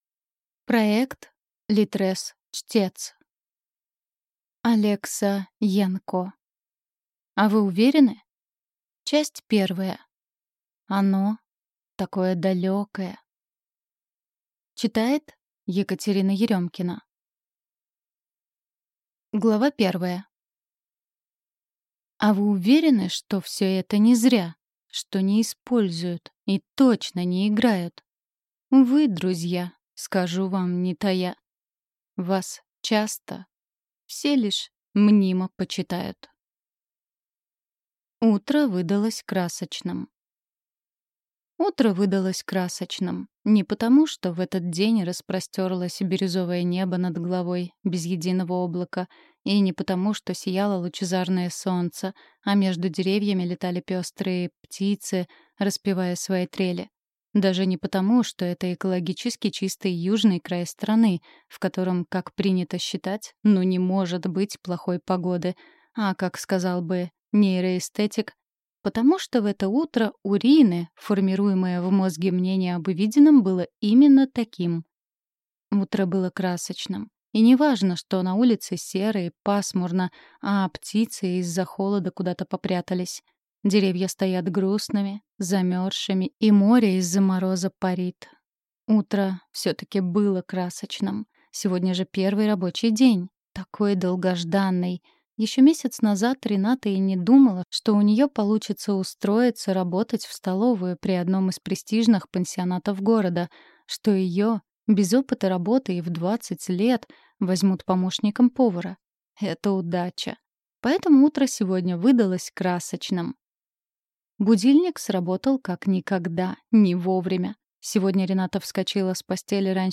Аудиокнига А вы уверены?! Часть 1. Оно, такое далекое…